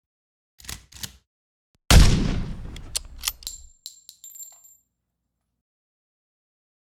Gun shot (sms).mp3